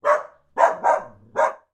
Лай соседского пса звуковой эффект